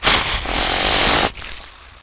hiss2.wav